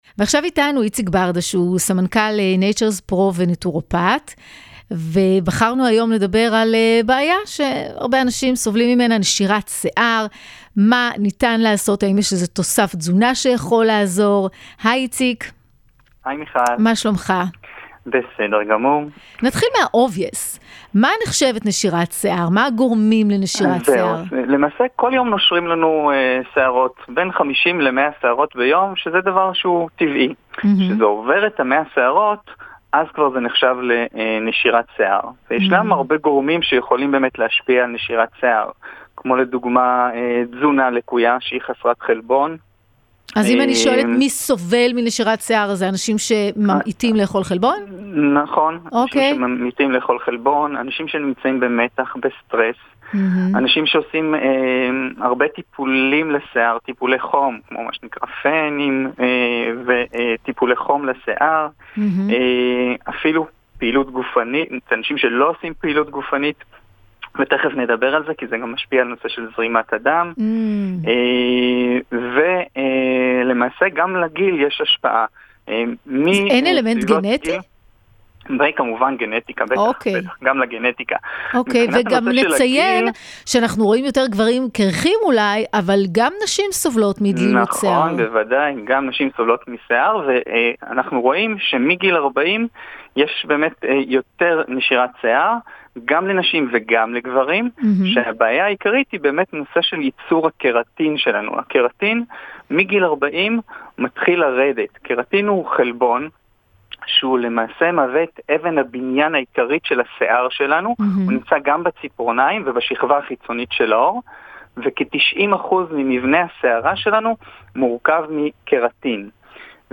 ראיונות ברדיו
ראיון